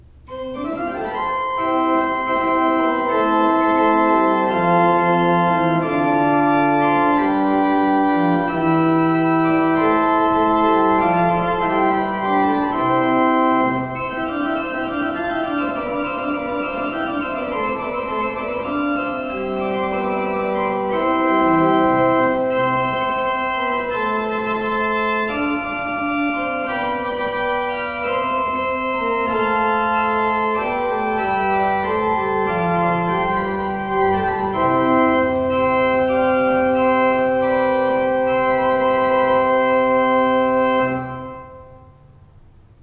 Listen to an improvisation on the Principal dulcis 8', Principal 4' and Octav 2' by clicking
Principal_Dulcis_8_Principal_4_Octav_2.wav